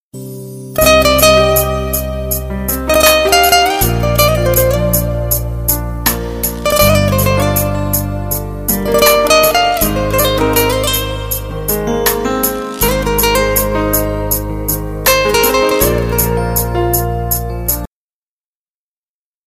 Play, download and share romantic guitar original sound button!!!!
romantic-guitar-1.mp3